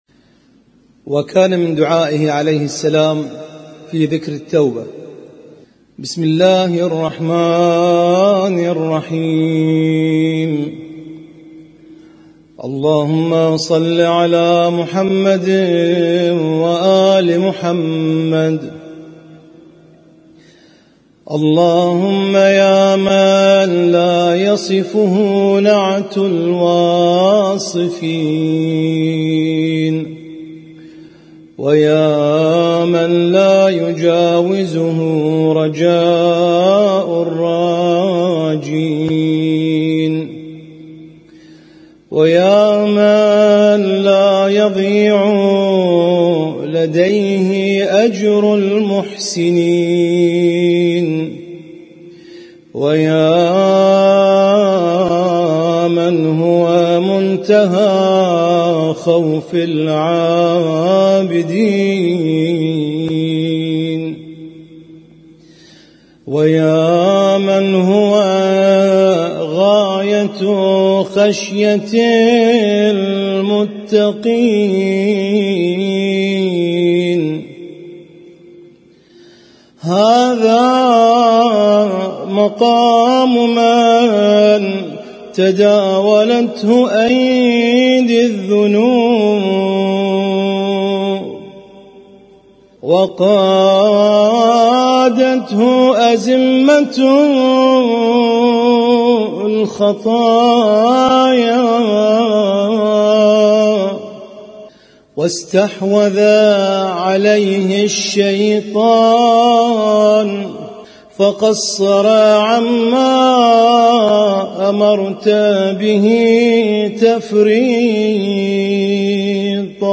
Husainyt Alnoor Rumaithiya Kuwait
اسم التصنيف: المـكتبة الصــوتيه >> الصحيفة السجادية >> الادعية السجادية